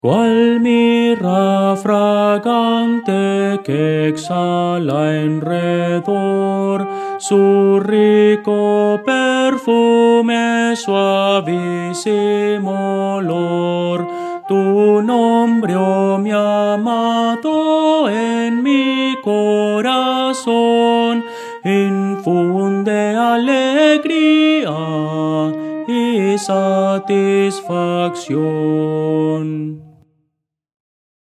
Música: MIDI
Voces para coro
Soprano –